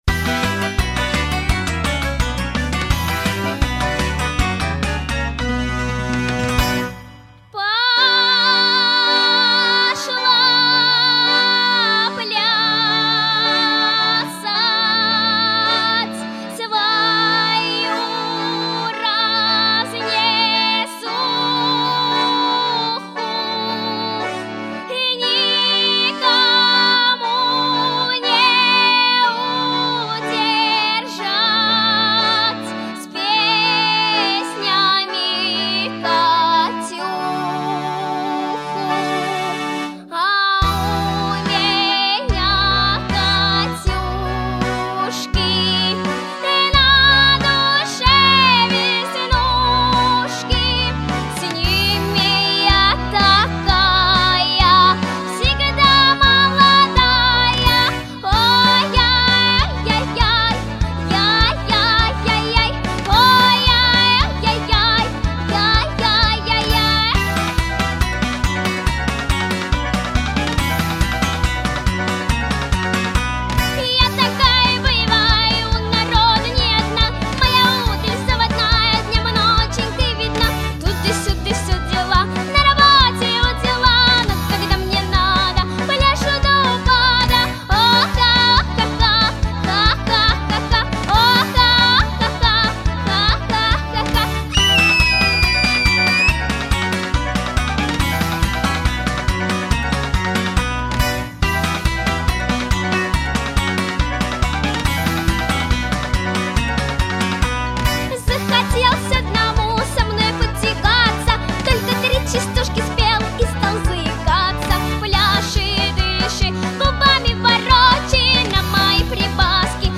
• Качество: Хорошее
• Категория: Детские песни
частушки